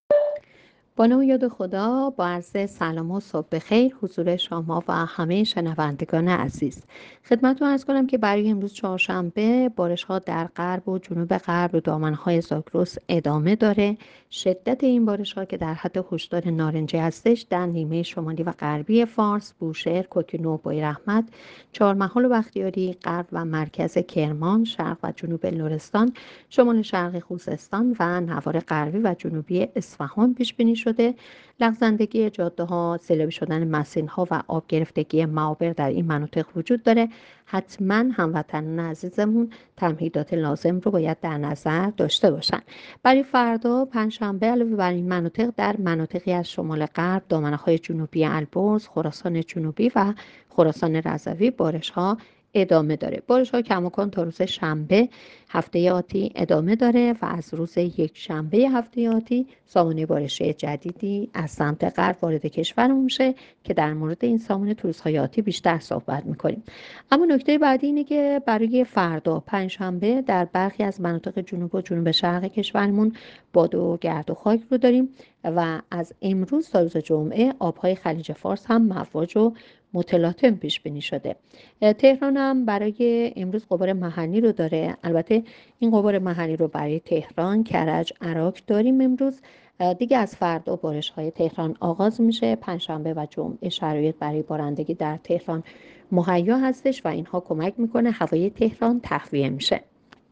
گزارش رادیو اینترنتی پایگاه‌ خبری از آخرین وضعیت آب‌وهوای ۲۴ بهمن؛